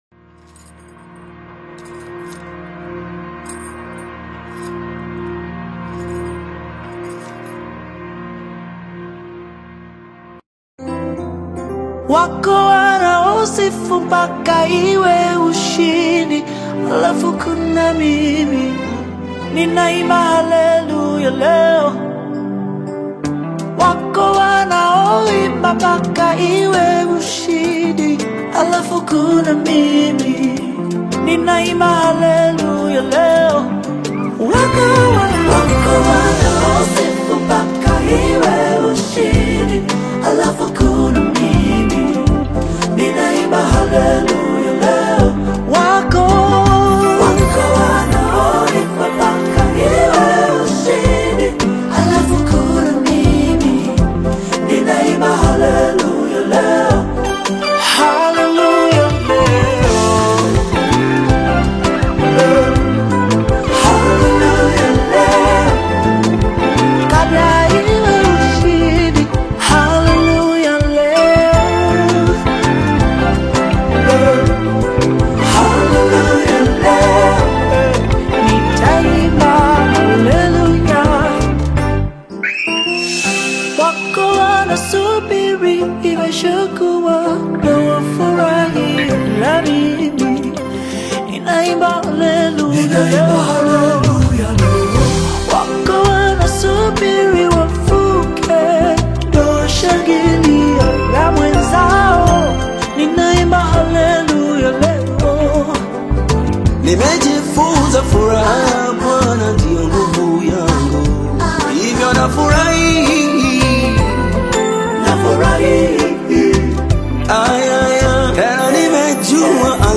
uplifting Tanzanian gospel/Afro-Pop collaboration
vibrant Swahili worship lyrics
blends melodic vocals with rhythmic Afro-gospel production